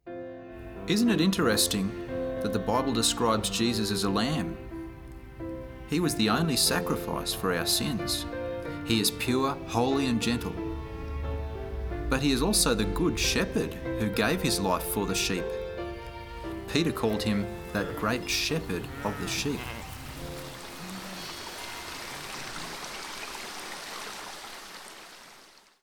36-The-Lord-Is-My-Shepherd-dialogue.mp3